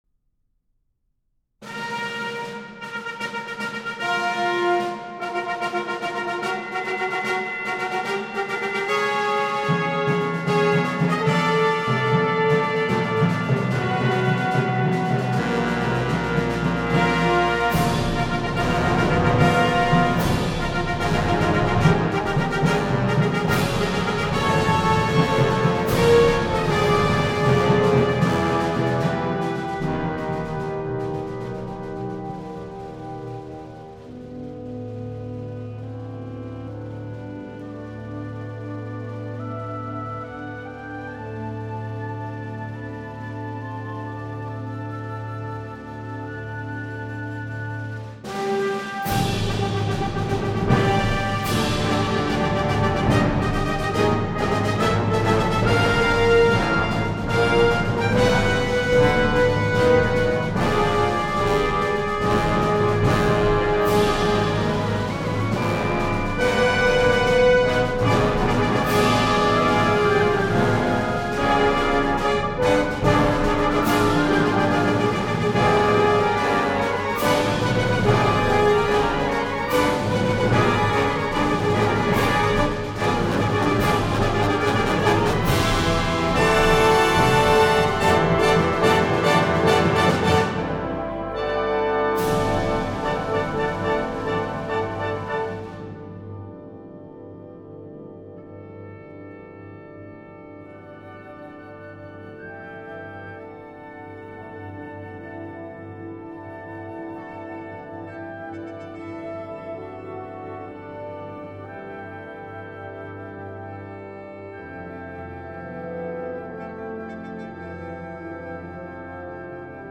編成：吹奏楽